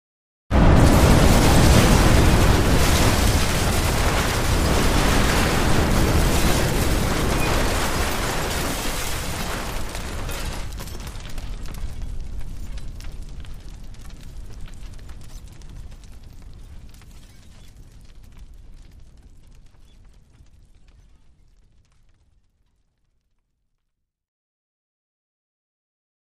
Building Explosion